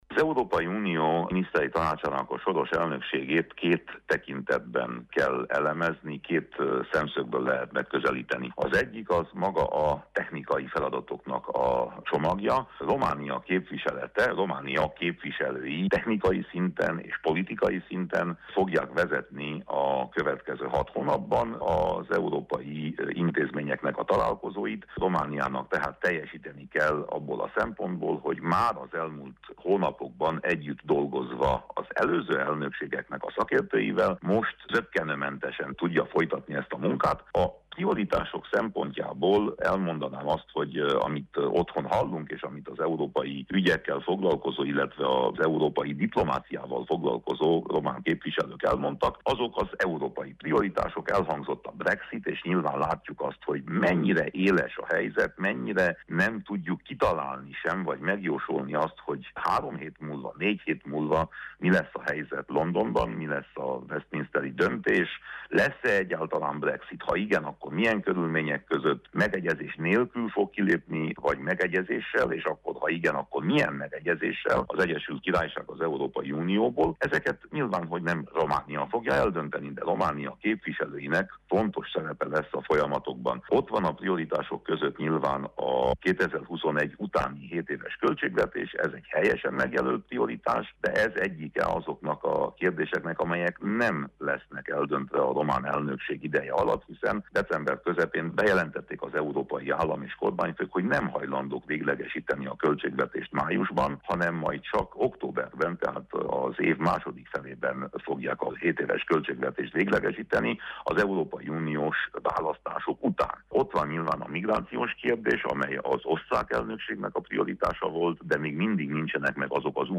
Winkler Gyula, Európai Parlamenti képviselőt kérdeztük.